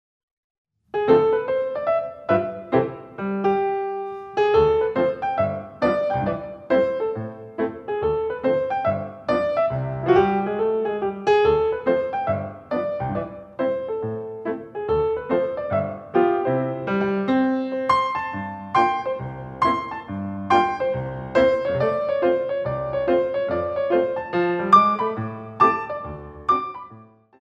Battements Tendus in the Centre